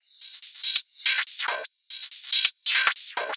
Index of /musicradar/uk-garage-samples/142bpm Lines n Loops/Beats
GA_BeatAFilter142-03.wav